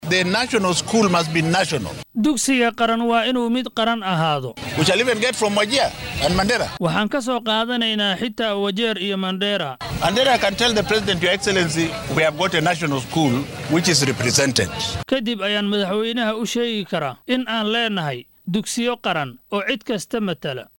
Xilli uu wasiirku arrimahan ka hadlayay ayuu yiri.